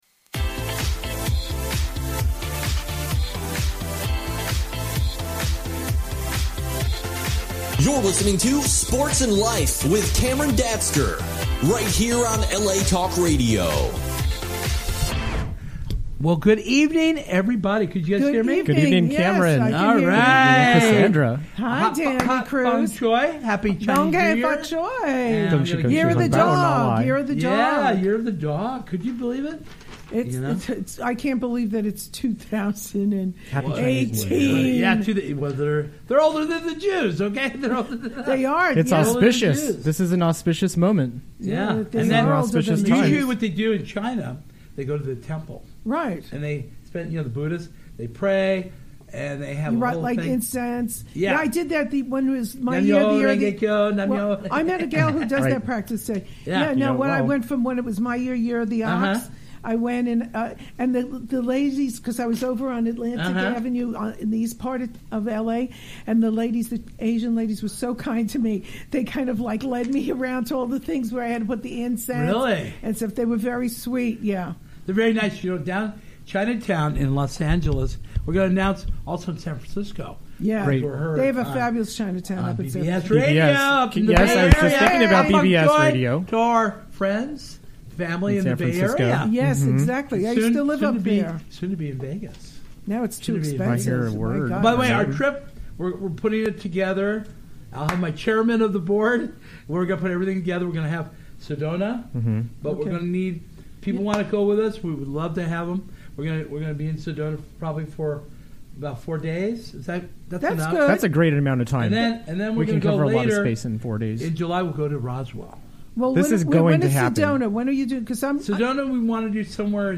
We will talk about UFO Sightings, The LA Life Consciousness Expo, UPARS meeting and We have some Musicians performing.